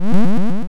bfxr_laserdeactivate.wav